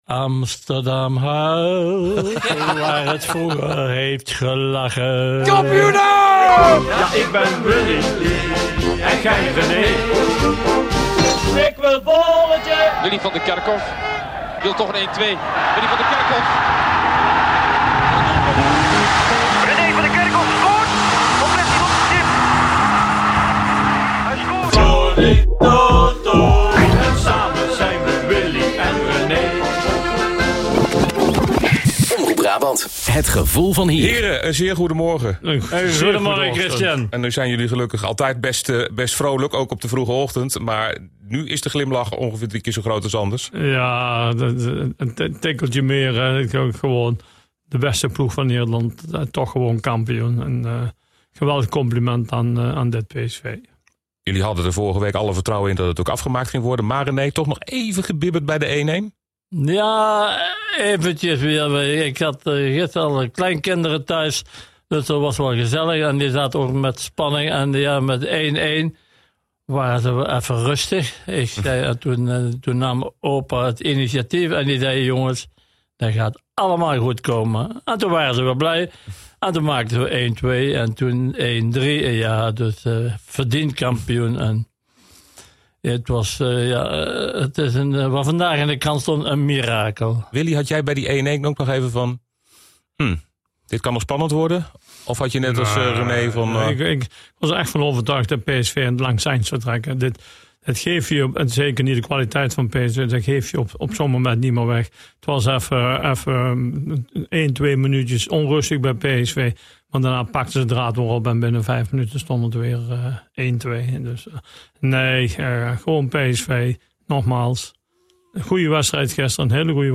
Luister naar Omroep Brabant regionaal nieuws, update vrijdagochtend 20 september 2024 om 11 minuten over half zeven. - 20.09.2024